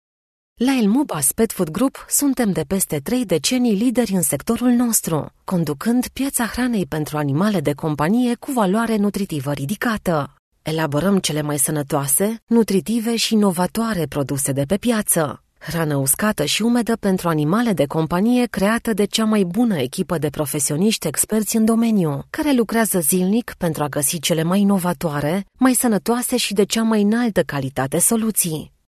Naturelle, Accessible, Fiable, Commerciale, Corporative
E-learning
Thanks to her extensive career in broadcasting, her voice has been trained for reliable authenticity and clarity.